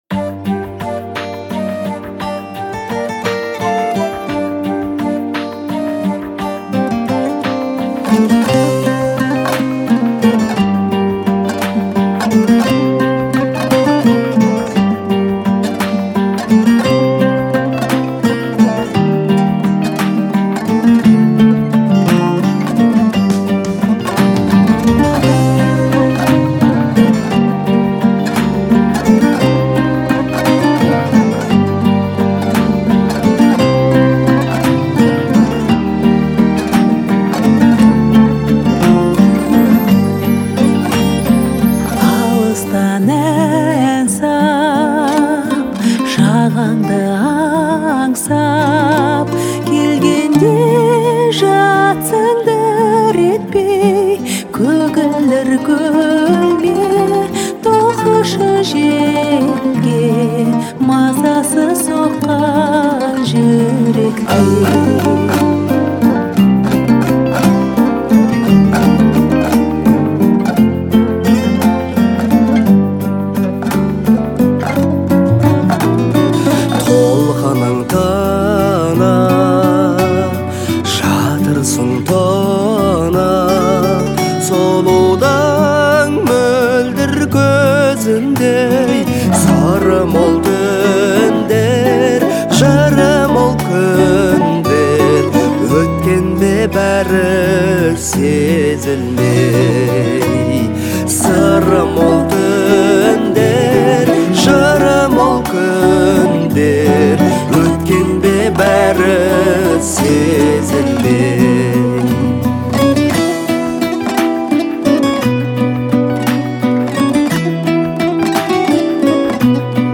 это мелодичный трек в жанре поп-фолк